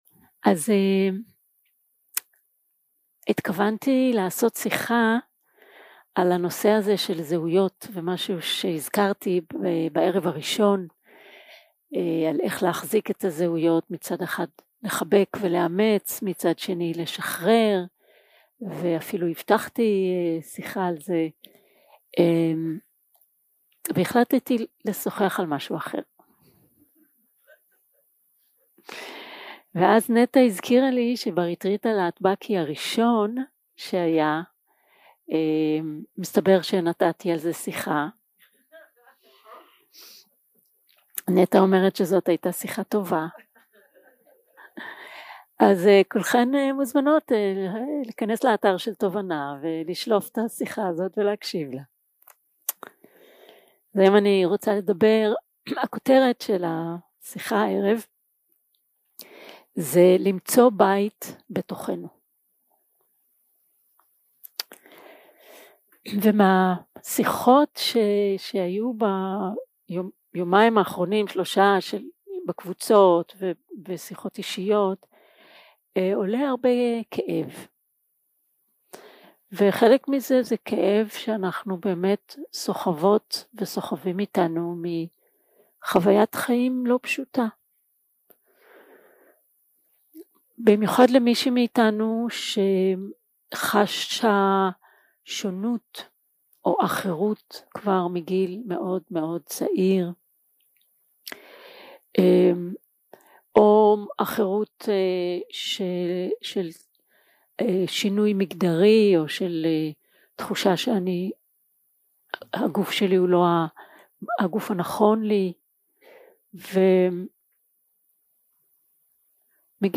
יום 3 - הקלטה 7 - ערב - שיחת דהרמה - למצוא בית בתוכנו
Dharma type: Dharma Talks שפת ההקלטה